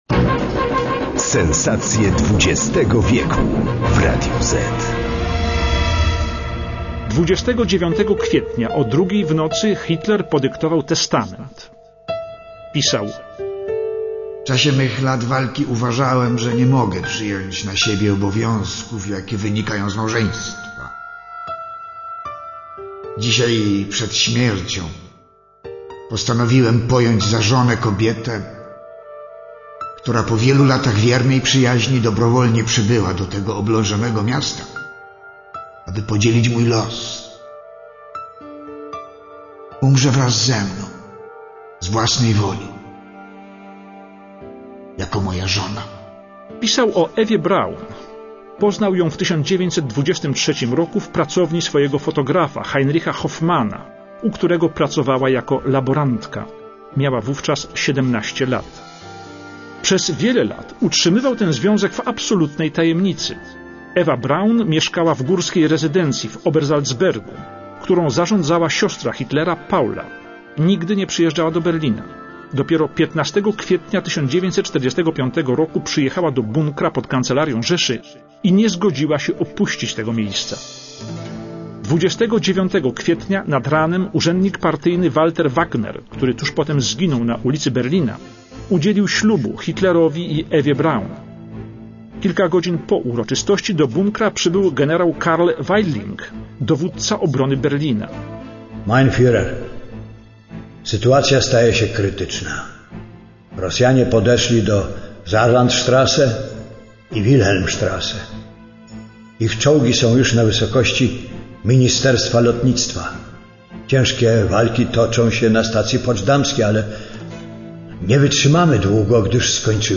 Adolf Hitler - Jan Peszek
Karl Weidling dowódca obrony Berlina - Witold Pyrkosz
Heinz Linge kamerdyner Hitlera - Marek Perepeczko
Pilot fuhrera Hans Baur - Krzysztof Globisz
żołnierz radziecki - Jerzy Bończak